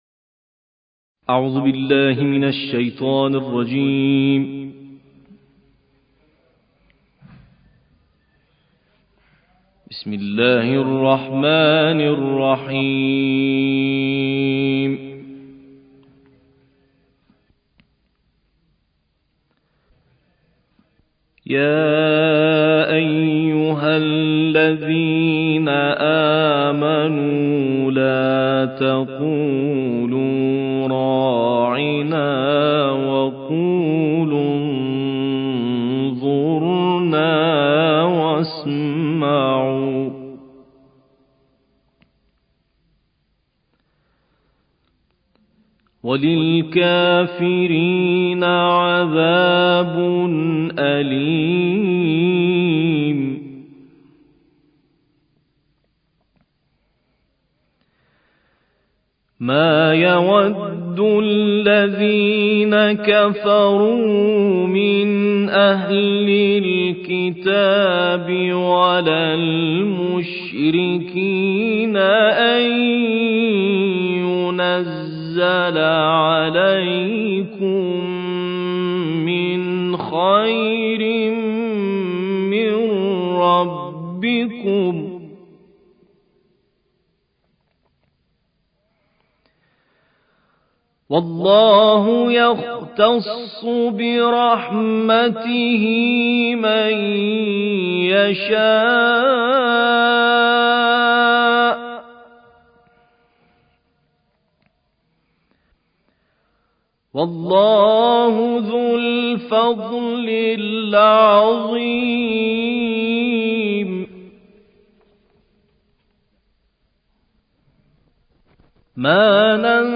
قران